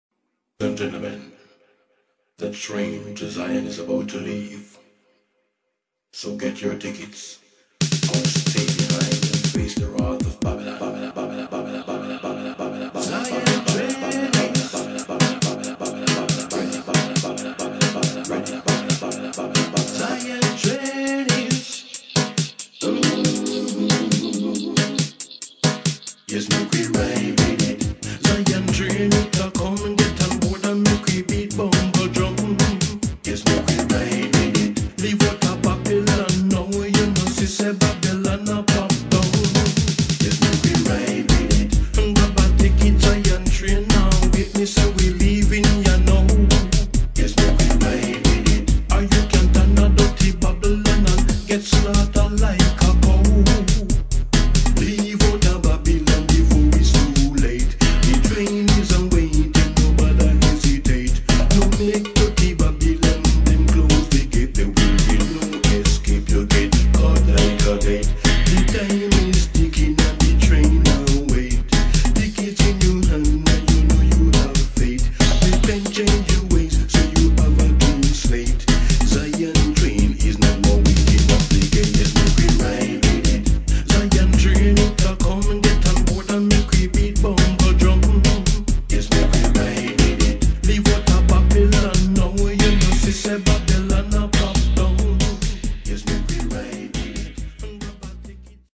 [ DUB / REGGAE ]